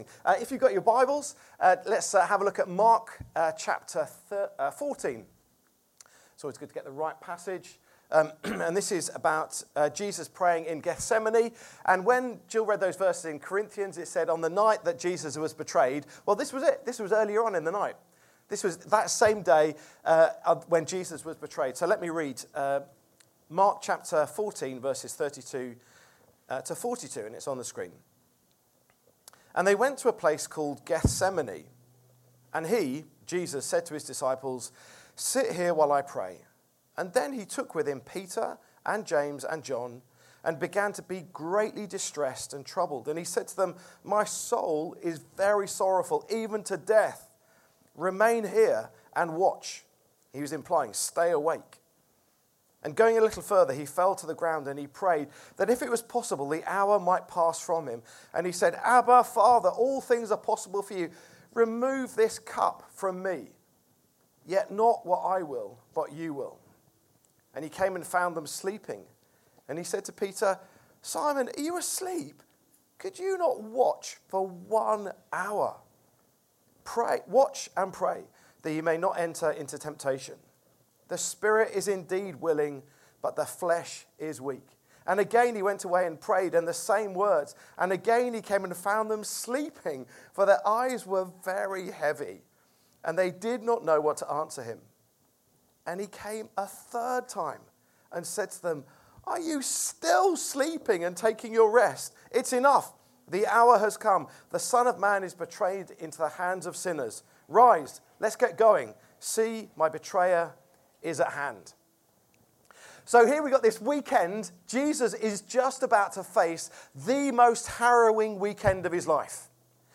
Download Gethsemane and the Sleeping Disciples | Sermons at Trinity Church